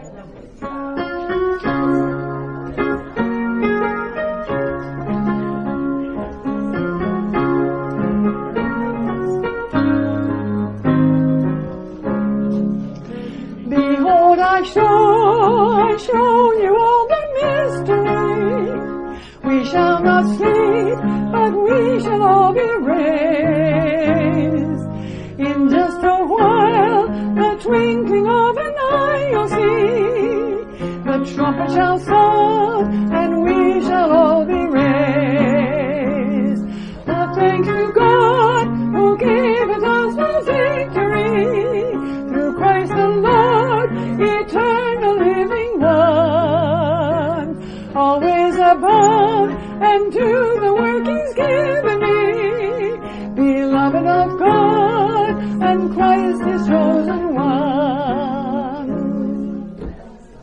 Hymn-3.mp3